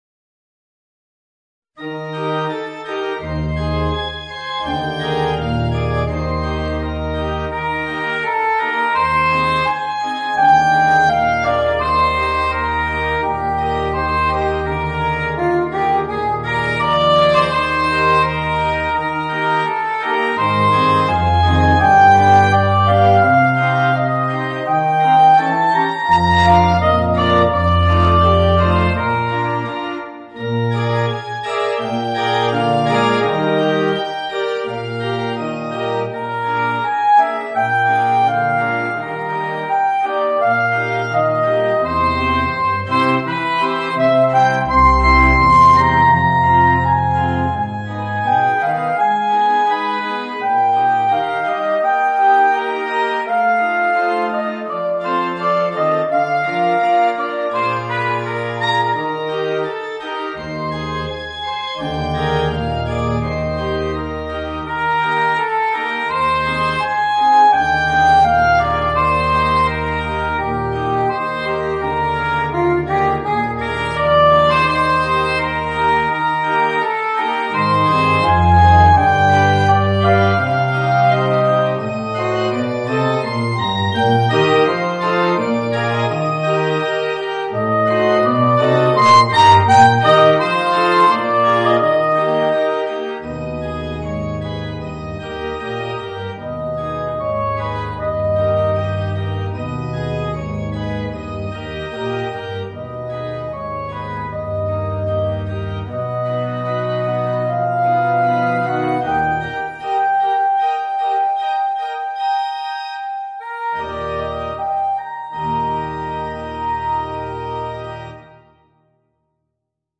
Gattung: für Sopransaxophon und Klavier oder Orgel